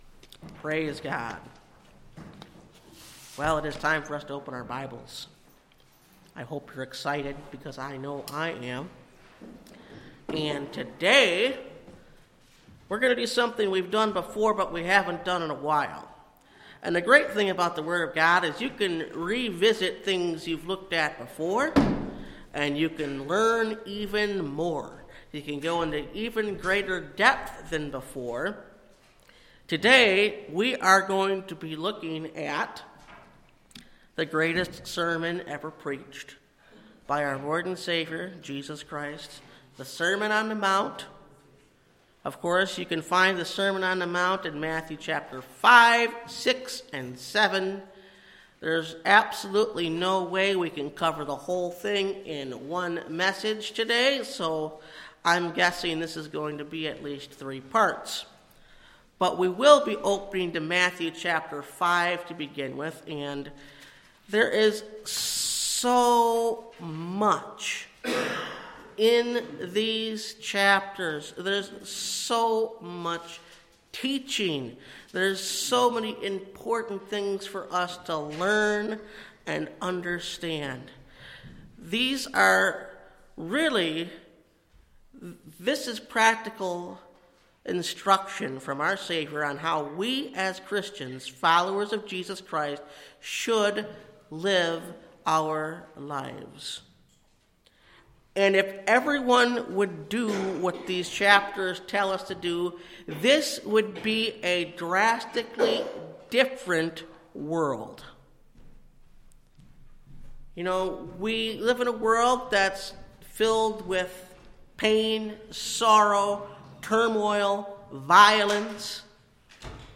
The Sermon On The Mount – Part 1 (Message Audio) – Last Trumpet Ministries – Truth Tabernacle – Sermon Library